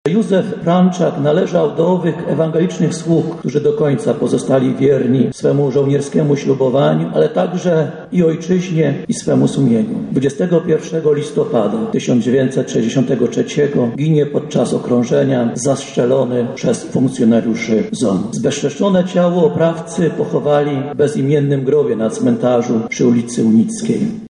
Msza pogrzebowa w jego intencji odbyła się w Archikatedrze Lubelskiej.
Historię Franczaka wspomniał podczas homilii
pogrzeb-lalka.mp3